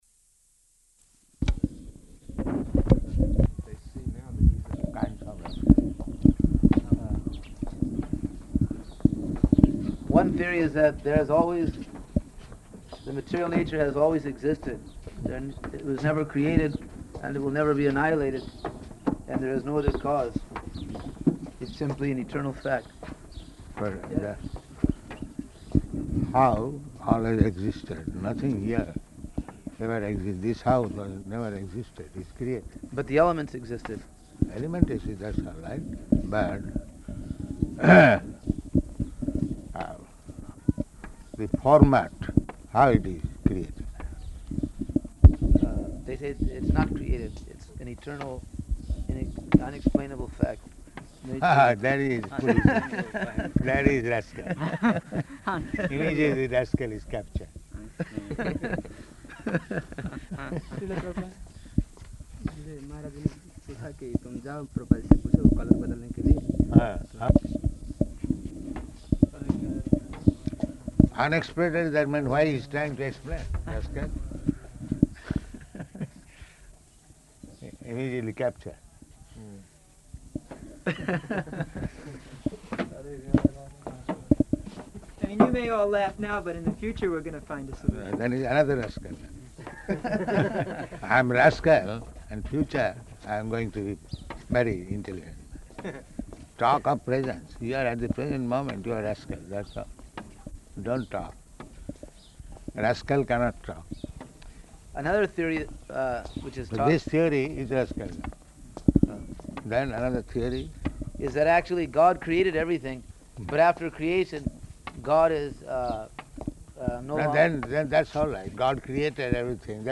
Morning Walk --:-- --:-- Type: Walk Dated: February 5th 1976 Location: Māyāpur Audio file: 760205MW.MAY.mp3 Prabhupāda: ...kinds of rascals.